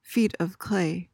PRONUNCIATION:
(FEET ov KLAY)